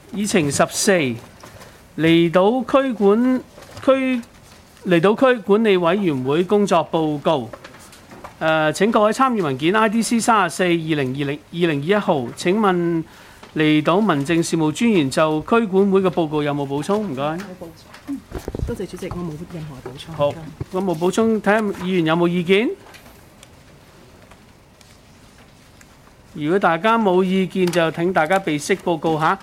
區議會大會的錄音記錄
離島區議會第二次會議
離島區議會會議室